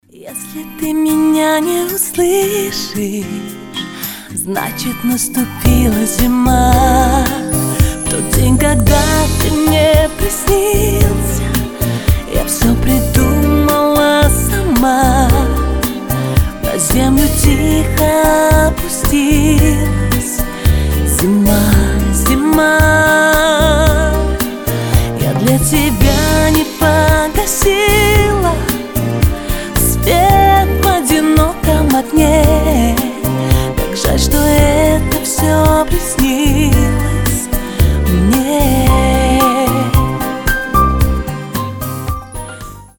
красивые
мелодичные
спокойные
медленные
нежные